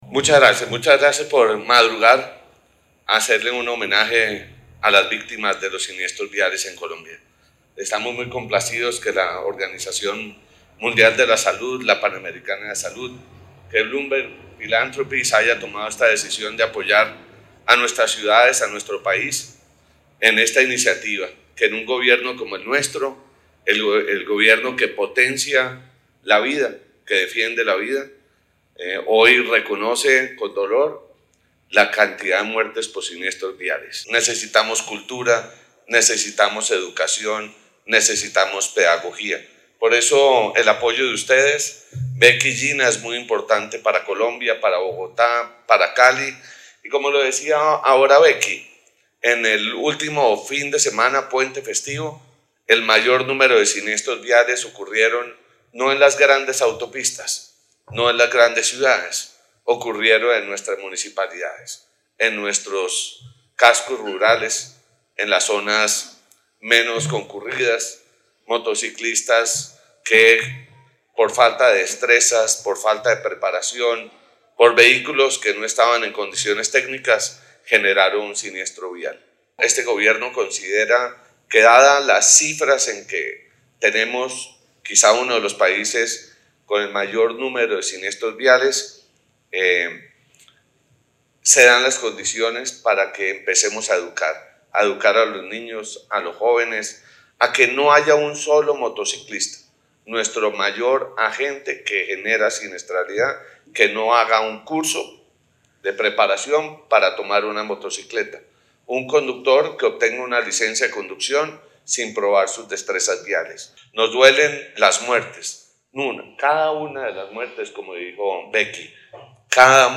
Durante la ceremonia, el ministro de Transporte, Guillermo Francisco Reyes González expresó el compromiso del Gobierno Nacional por fortalecer y avanzar en nuevas acciones en seguridad vial con el fin de reducir las muertes en las vías del país.
Declaración del ministro de Transporte, Guillermo Francisco Reyes González.